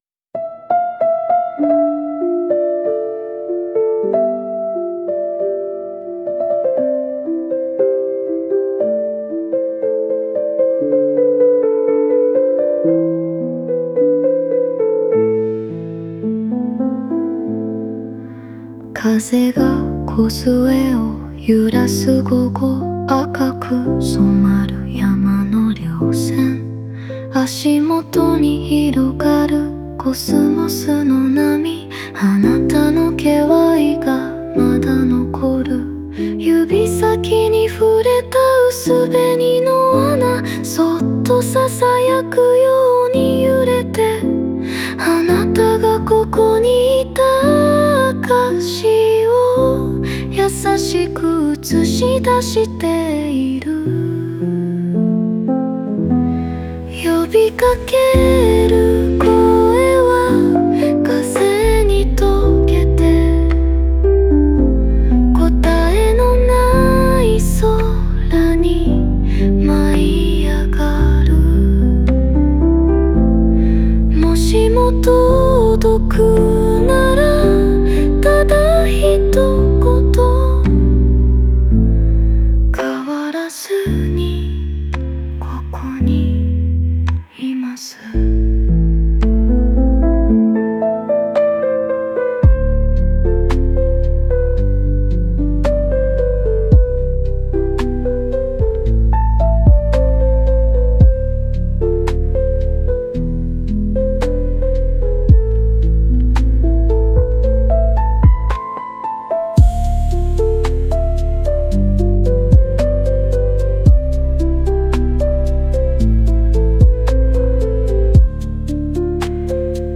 Music(音楽) Music(音楽) (1630)